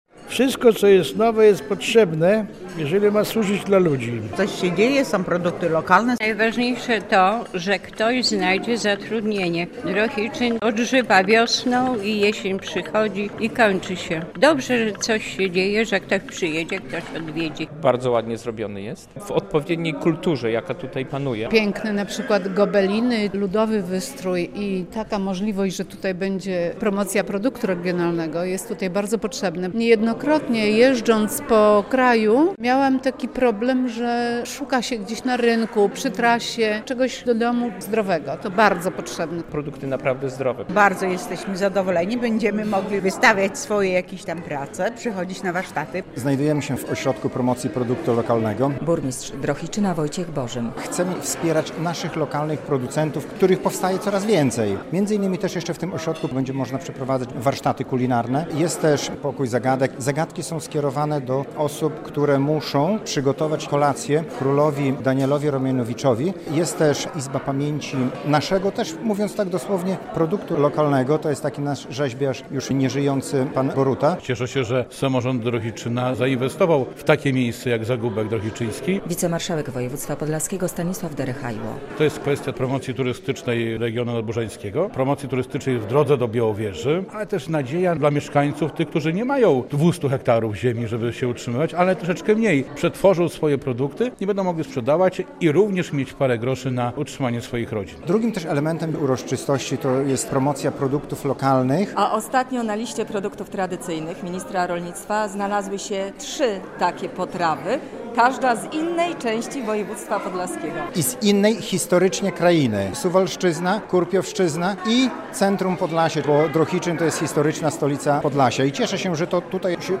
Podczas uroczystości otwarcia zaprezentowano też trzy produkty z naszego regionu, które niedawno trafiły na Listę Produktów Tradycyjnych Ministra Rolnictwa i Rozwoju Wsi.